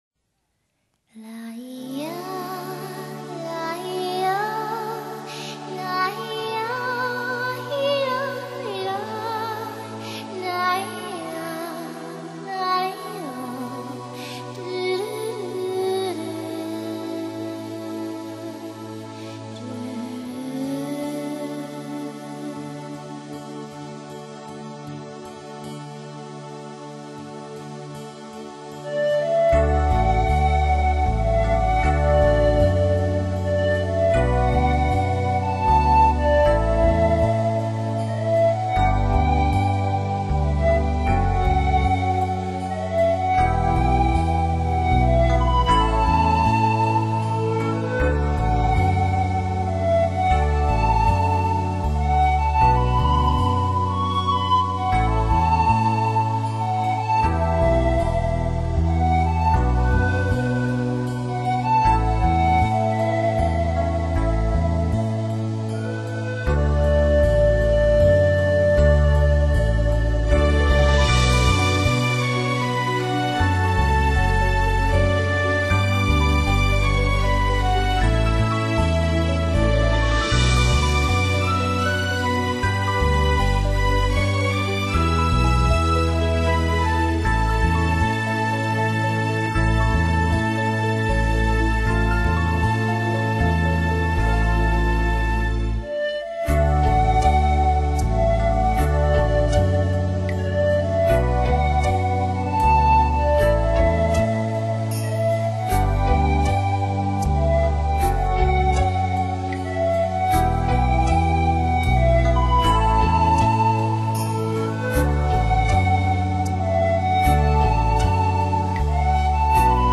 歌手：纯音乐
风格：自然/灵魂音乐